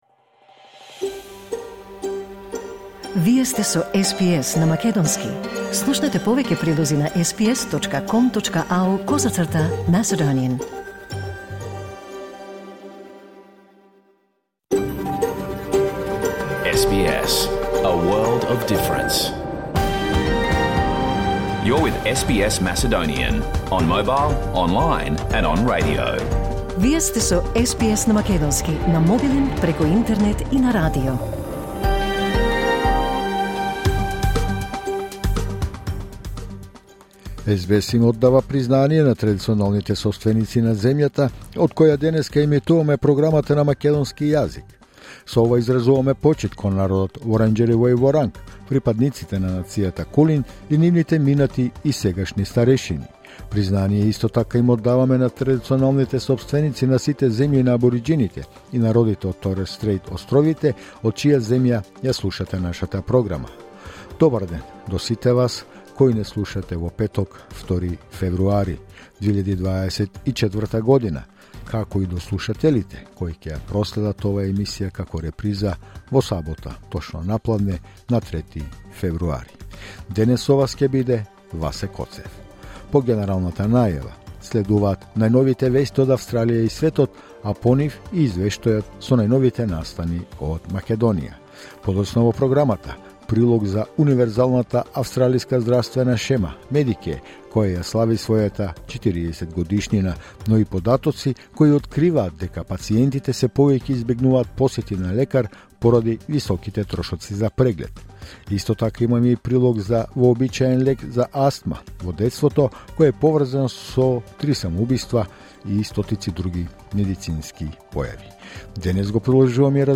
SBS Macedonian Program Live on Air 2 February 2024